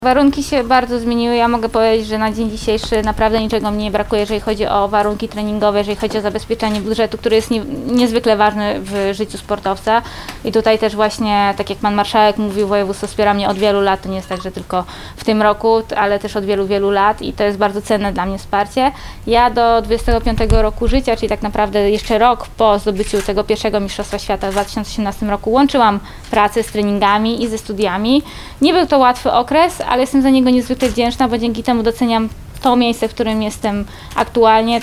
Mistrzyni olimpijska w Lublinie – konferencja prasowa z Aleksandrą Mirosław